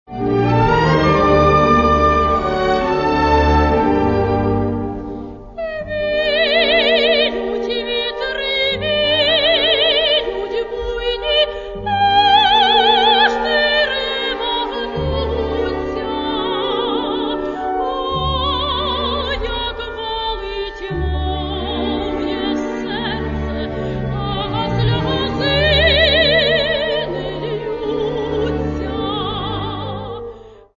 Каталог -> Класична -> Опера та вокал